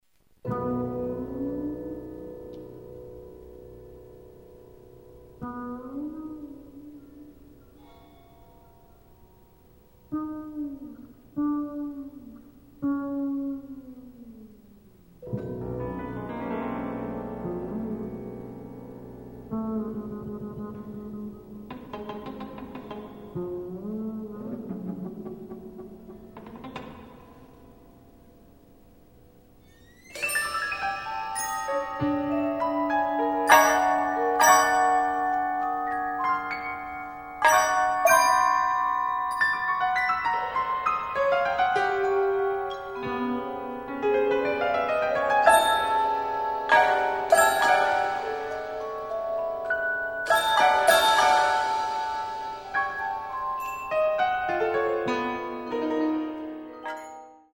Genre: Percussion Ensemble
# of Players: 10